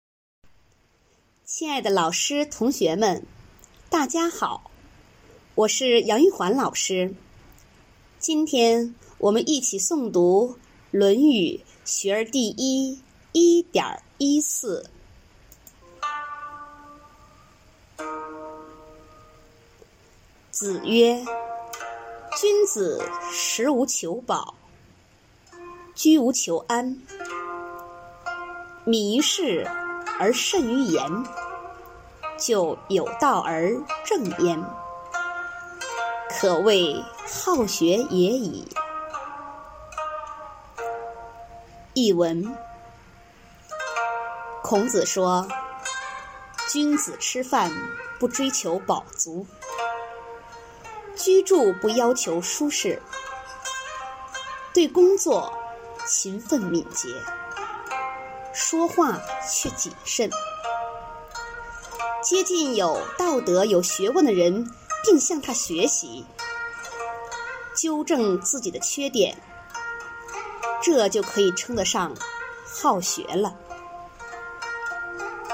每日一诵0305.mp3